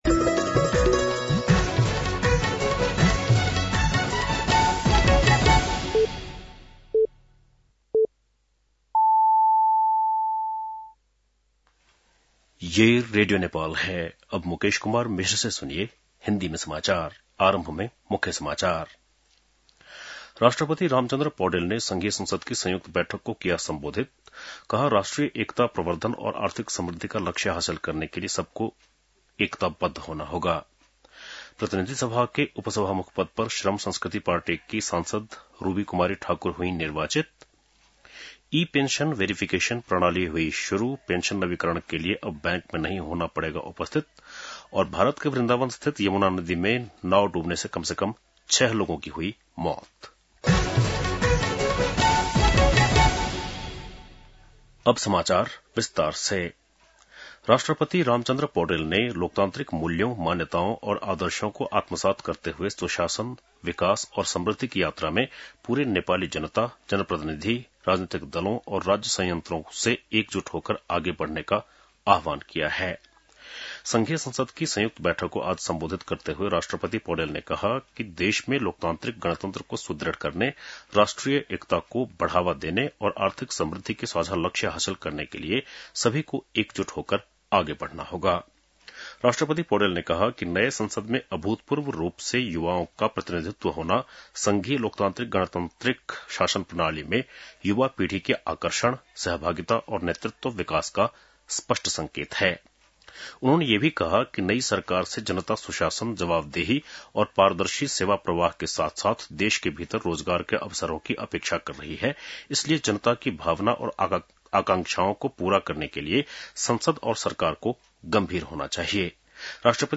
बेलुकी १० बजेको हिन्दी समाचार : २७ चैत , २०८२